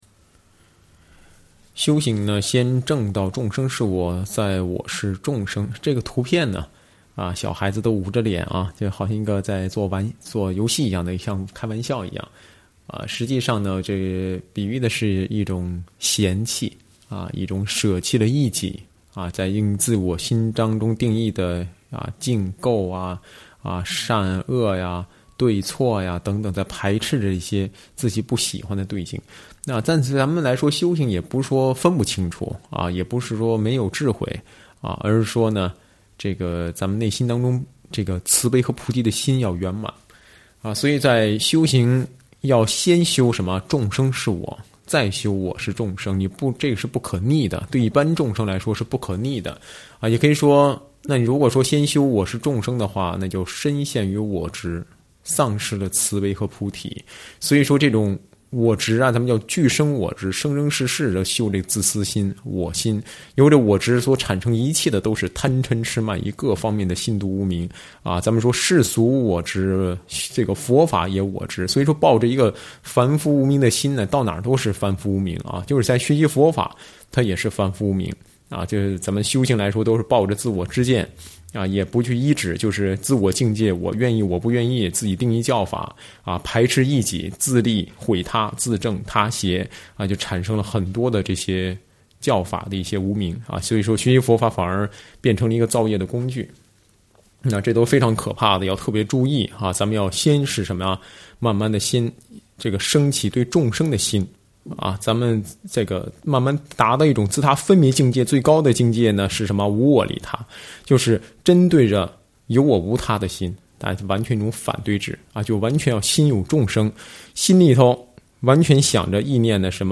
上师语音开示